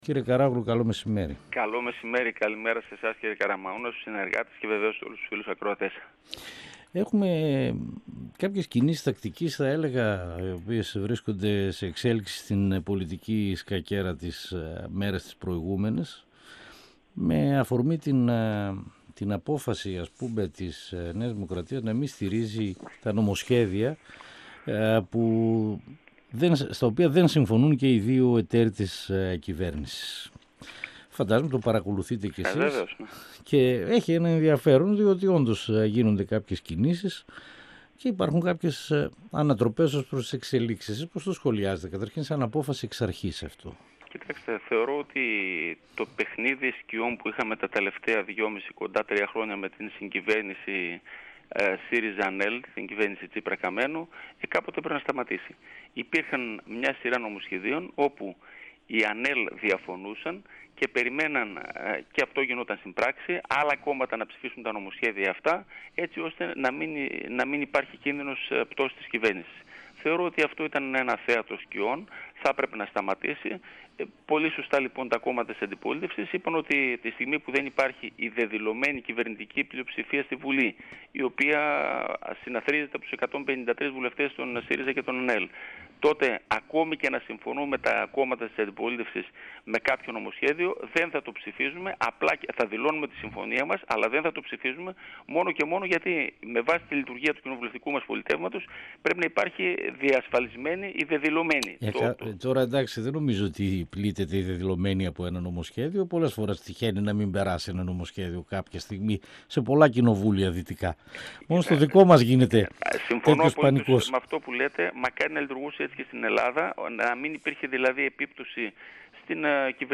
Ο βουλευτής Β’ Θεσσαλονίκης της ΝΔ Θεόδωρος Καράογλου στον 102 fm της ΕΡΤ3